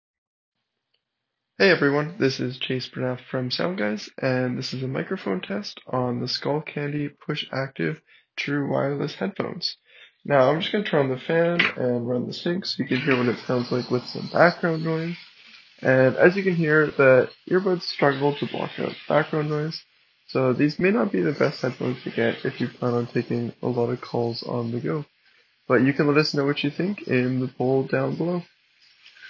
Skullcandy-Push-Active-mic-test.m4a